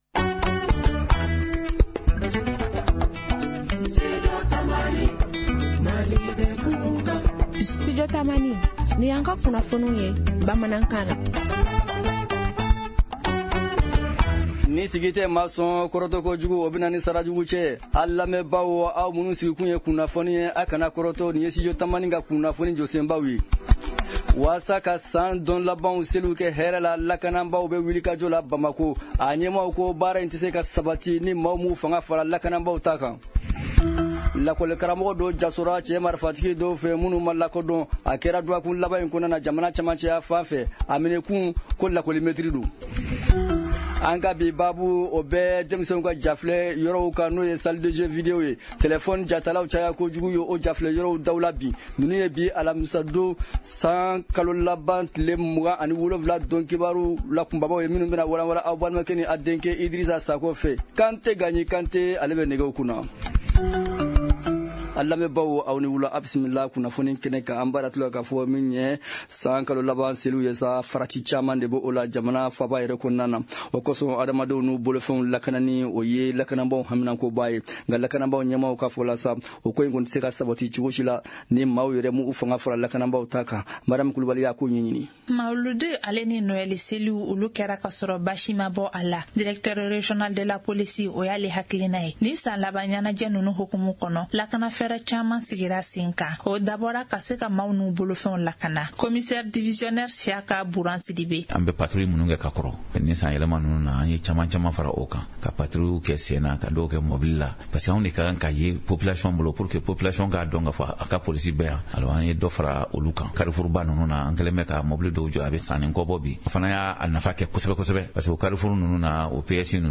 Journal en français: Télécharger